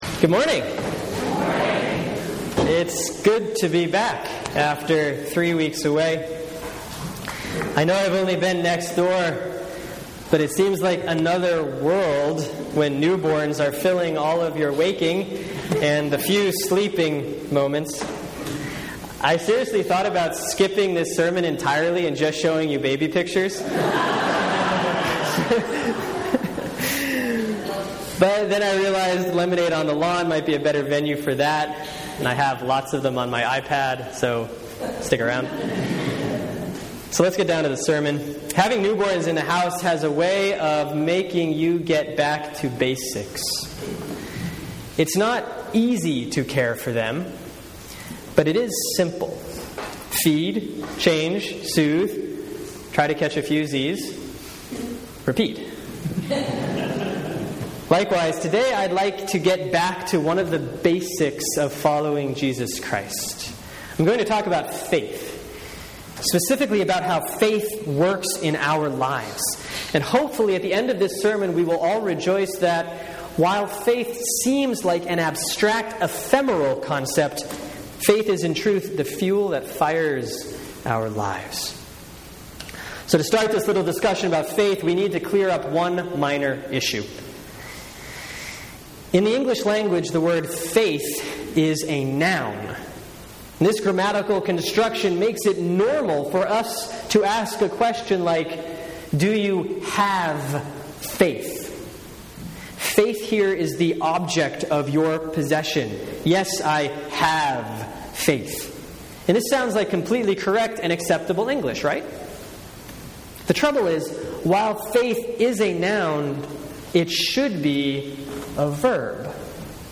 Sermon for Sunday, August 17, 2014 || Proper 15A || Matthew 10:21-28